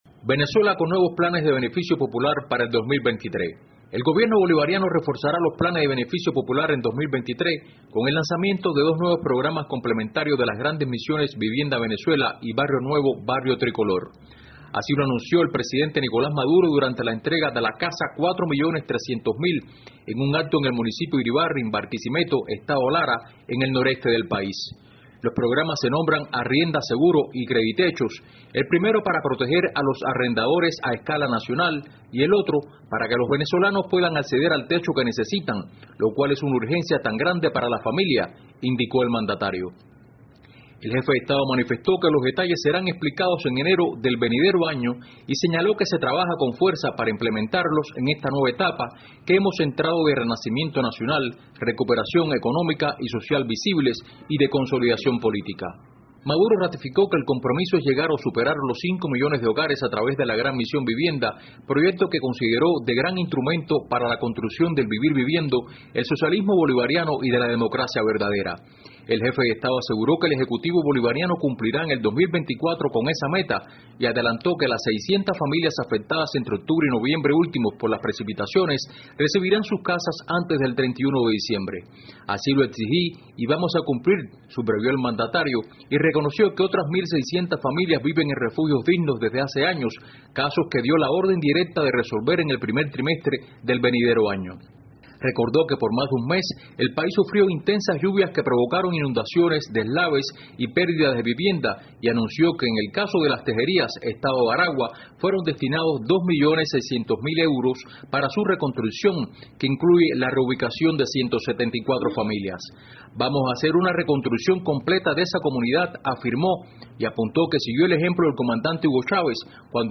desde Caracas